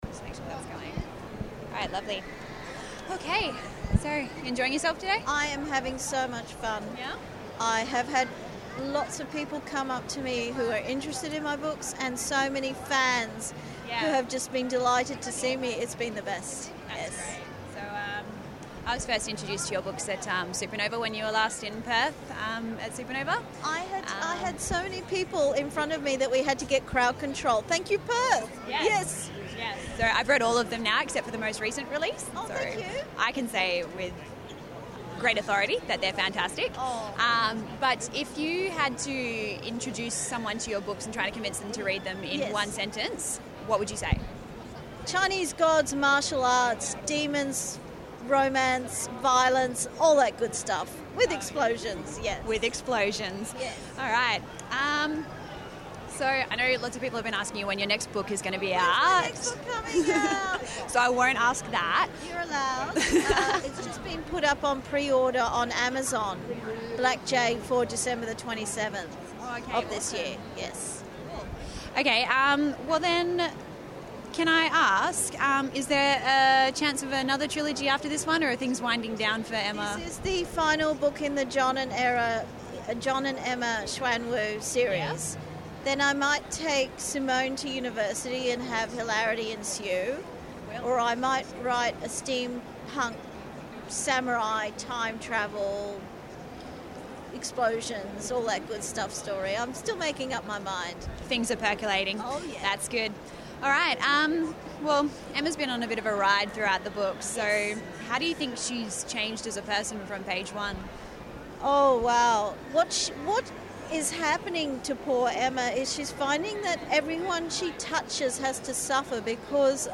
Over the weekend, we at the House of Geekery had the pleasure of catching up with best selling author, Kylie Chan, for a chat about her books and her work as a writer.
Category : Convention, Geek Lit., Interviews, Legends of Geekdom
kylie-chan-house-of-geekery-oz-comiccon.mp3